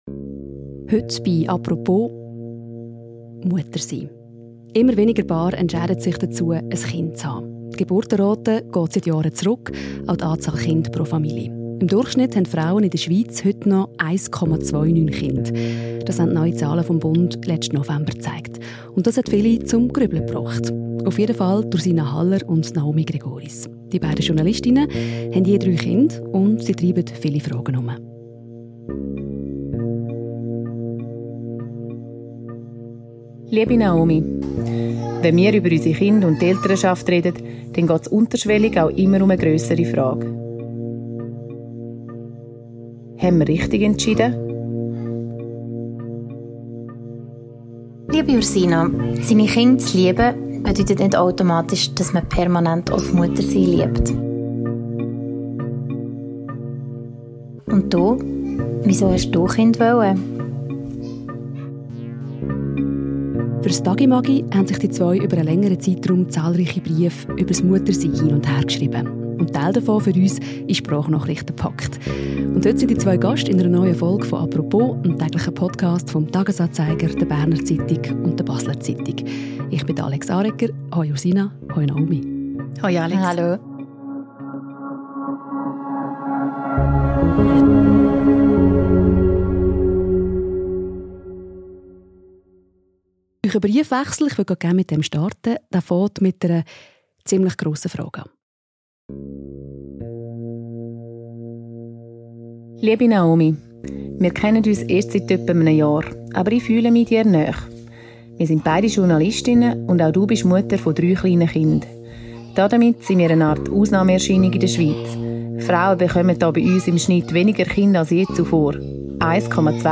Ein Gespräch über die schönen und unschönen Seiten des Mutterseins.